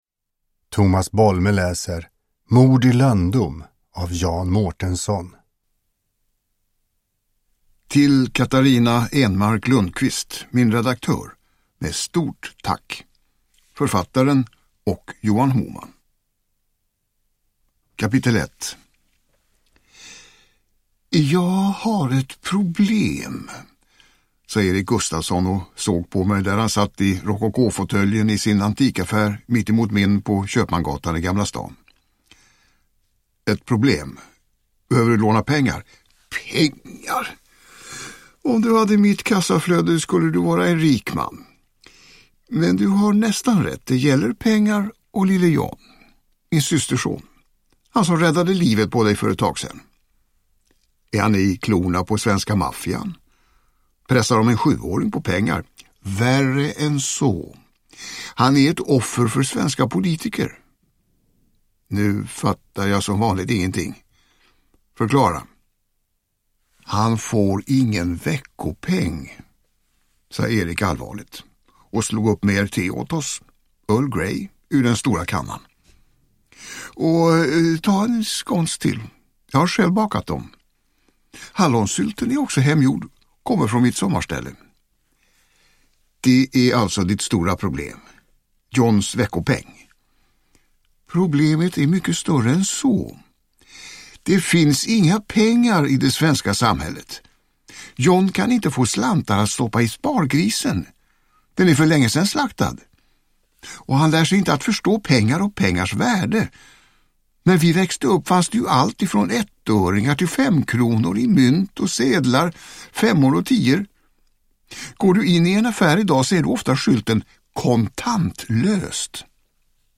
Uppläsare: Tomas Bolme
Ljudbok